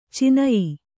♪ cinayi